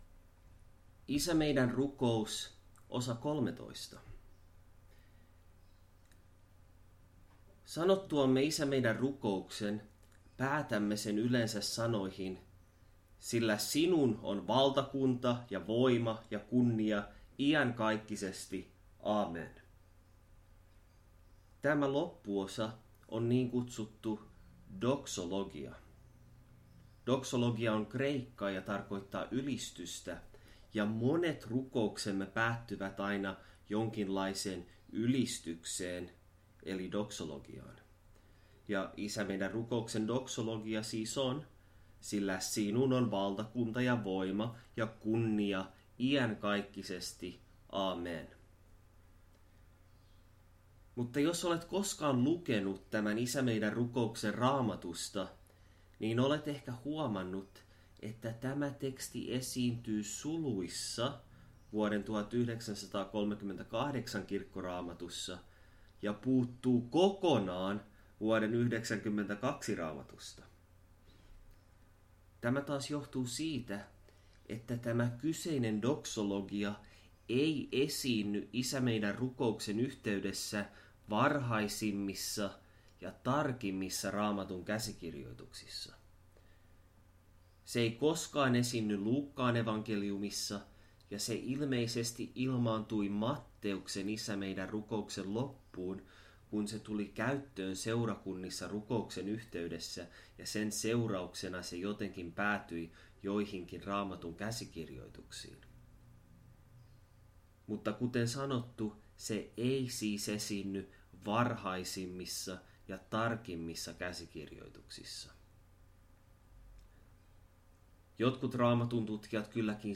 Saarna: Isä Meidän -rukous, Osa 13: Sillä sinun on valtakunta ja voima ja kunnia iankaikkisesti.